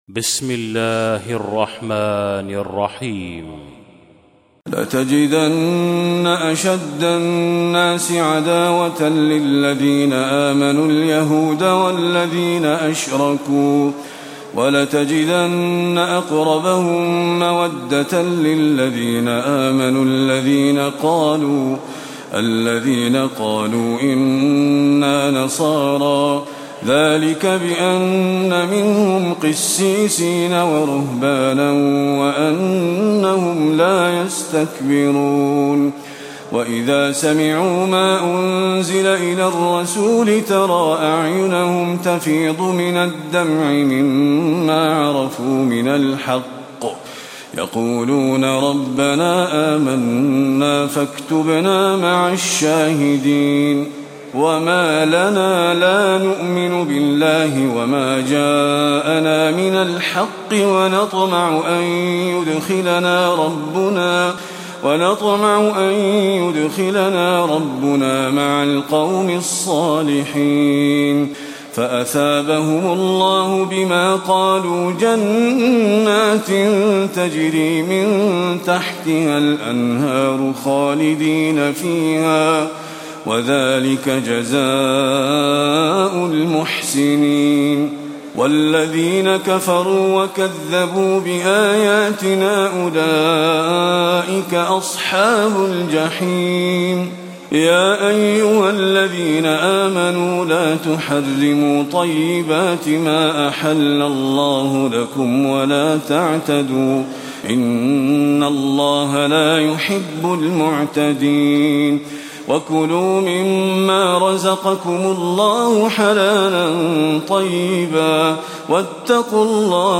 تلاوة سورة المائدة من آية 82 إلى أخرها
تاريخ النشر ١ محرم ١٤٣٧ هـ المكان: المسجد النبوي الشيخ